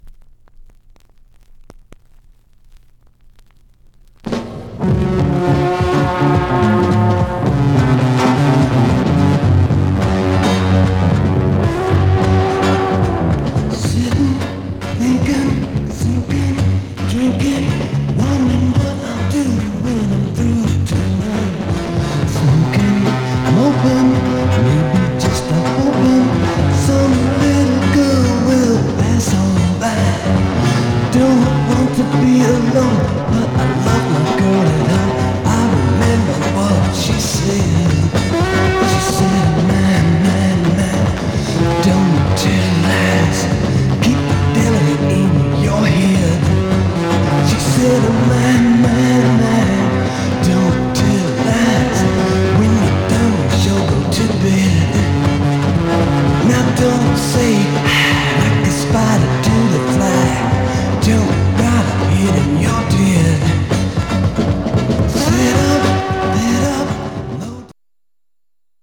Some surface noise/wear Stereo/mono Mono
Garage, 60's Punk